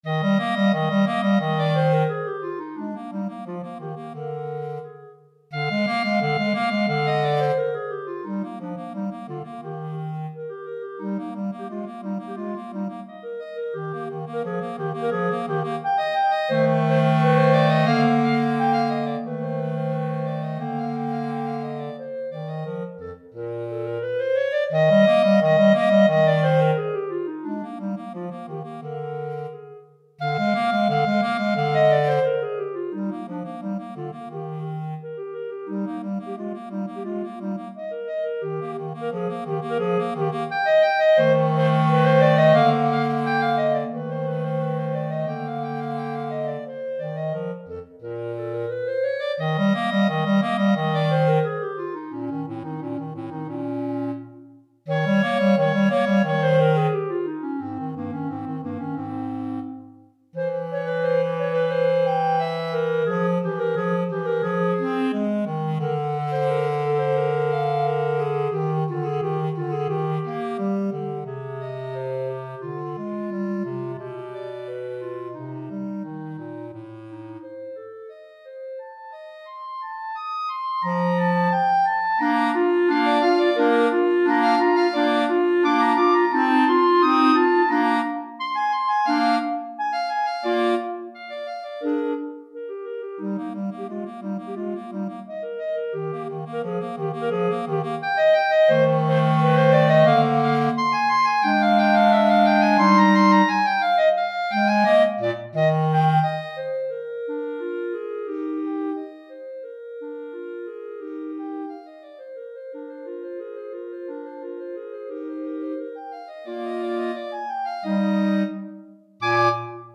2 Clarinettes en Sib et Clarinette Basse